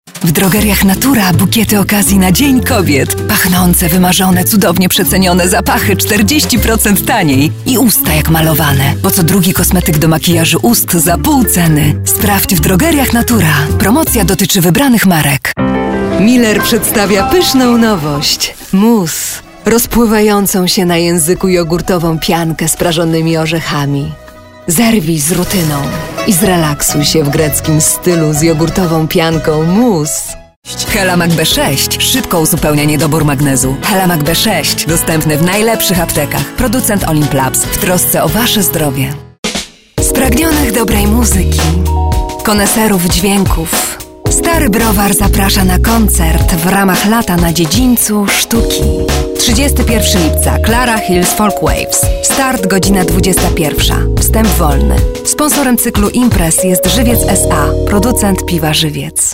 Female 30-50 lat